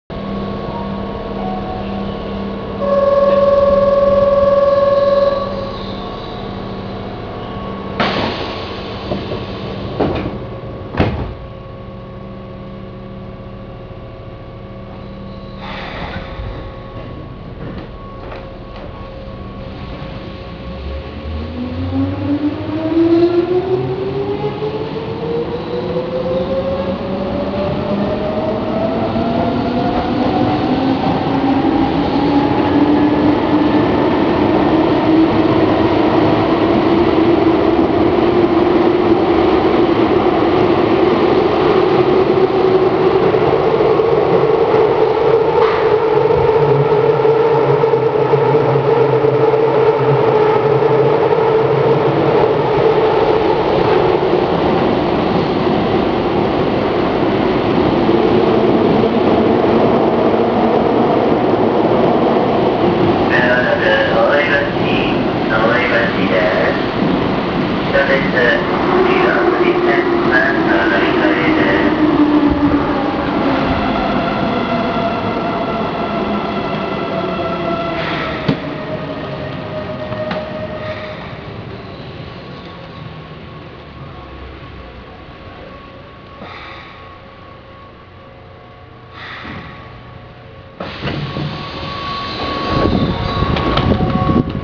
・5000系走行音
【中之島線】渡辺橋〜大江橋（1分34秒：515KB）
抵抗制御のように聞こえますが、一応更新の際に界磁になっています。ドア上のLED表示や停車時に流れ続けるドアチャイム等も搭載されています。